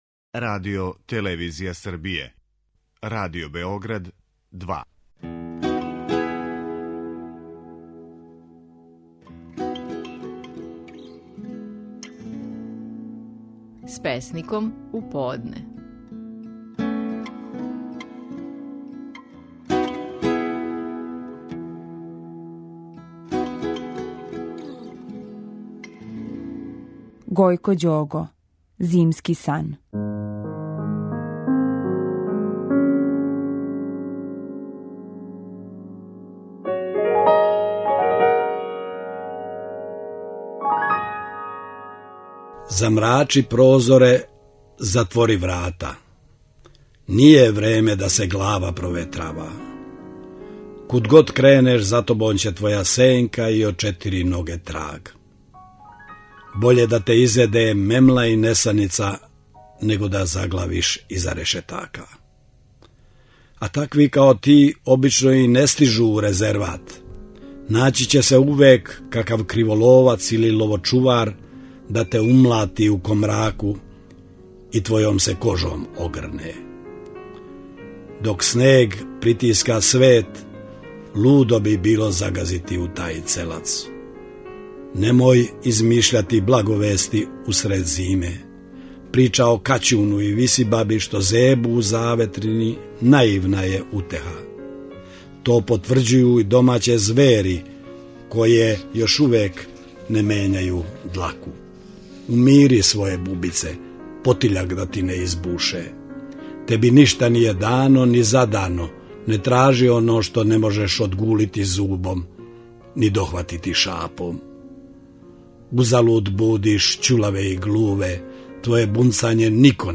Стихови наших најпознатијих песника, у интерпретацији аутора.
Гојко Ђого тумачи свој „Зимски сан".